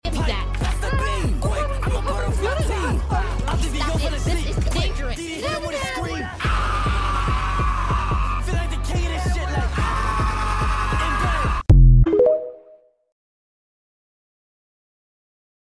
Firey Definitely Has The Best Scream!